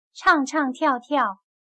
唱唱跳跳\chàng chàng tiào tiào\cantar y bailar